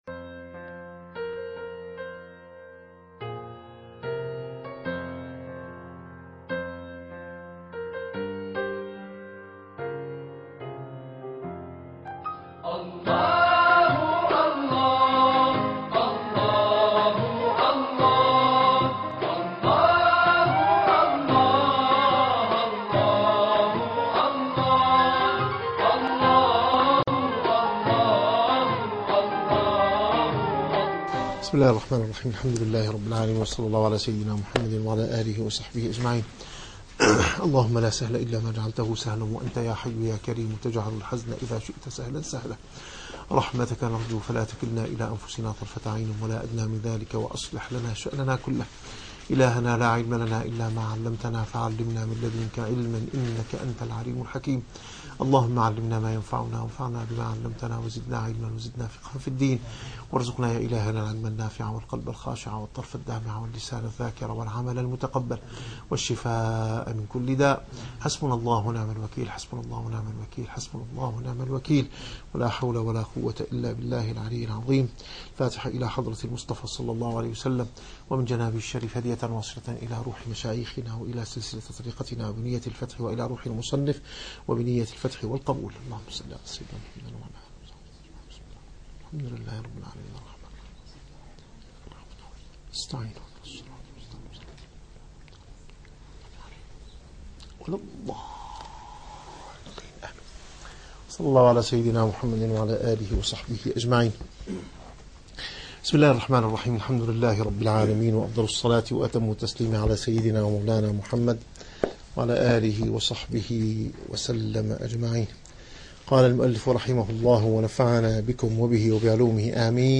- الدروس العلمية - الرسالة القشيرية - الرسالة القشيرية / الدرس الثاني والأربعون.